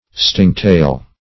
stingtail - definition of stingtail - synonyms, pronunciation, spelling from Free Dictionary Search Result for " stingtail" : The Collaborative International Dictionary of English v.0.48: Stingtail \Sting"tail`\, n. (Zool.)